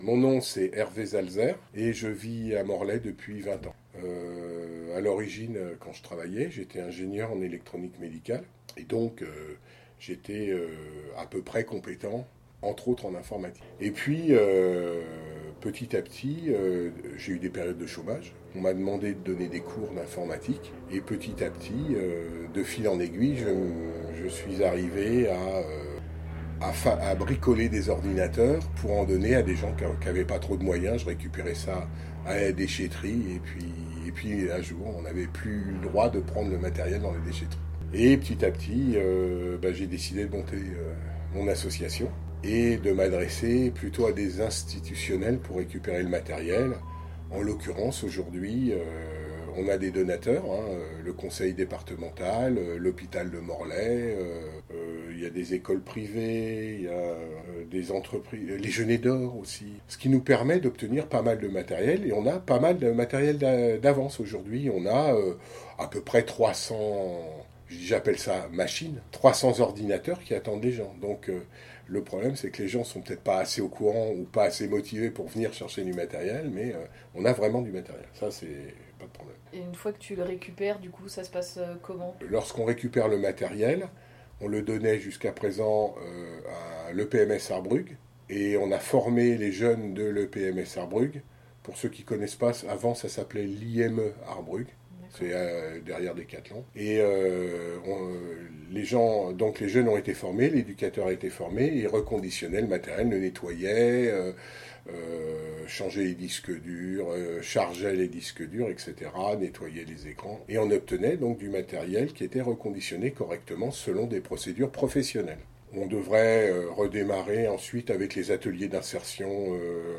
Interview audio